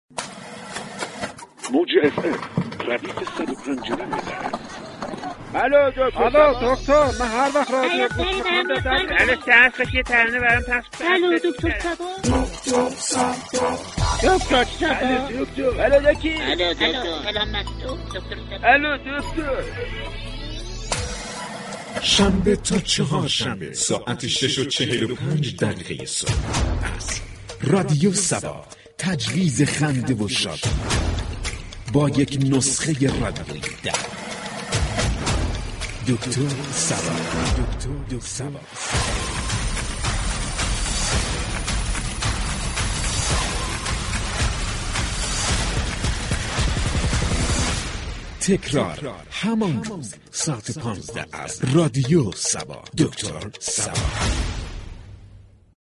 برنامه «دكتر صبا» صبا حسادت را سوژه طنز خود قرار می دهد